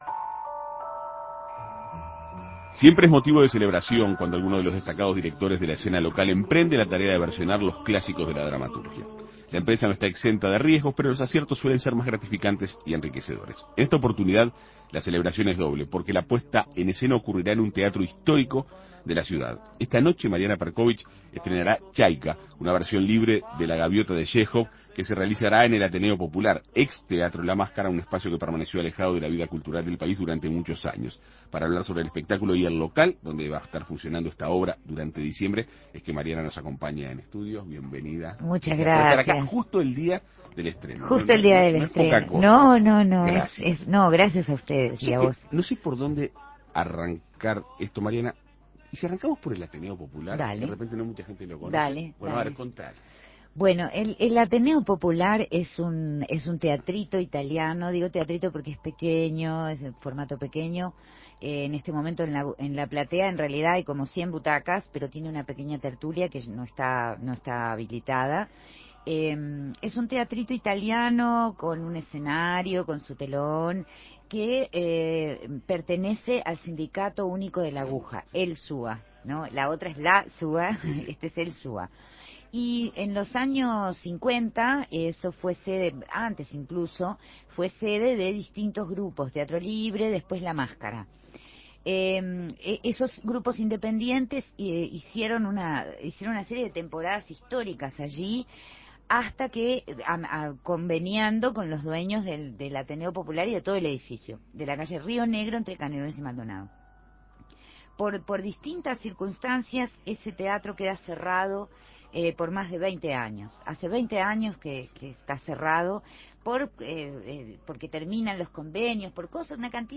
Para conocer los detalles de la propuesta, En Perspectiva Segunda Mañana dialogó con la artista.